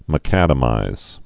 (mə-kădə-mīz)